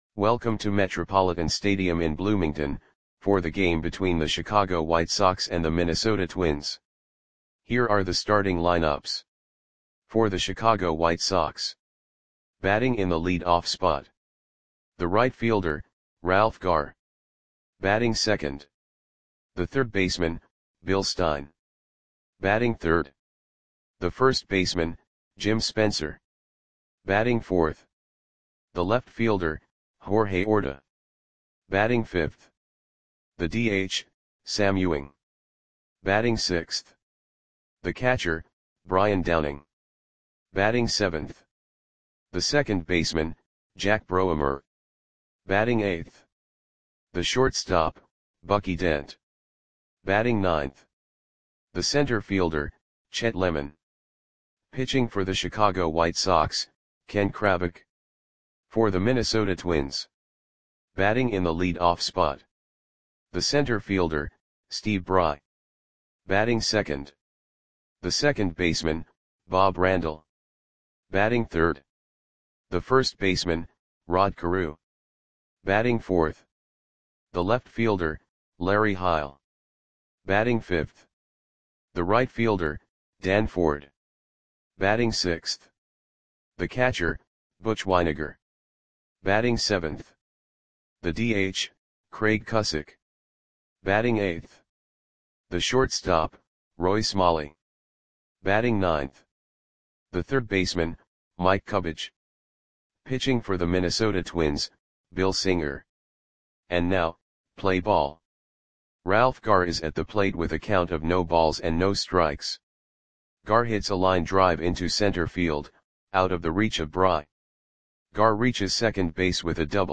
Lineups for the Minnesota Twins versus Chicago White Sox baseball game on September 6, 1976 at Metropolitan Stadium (Bloomington, MN).
Click the button below to listen to the audio play-by-play.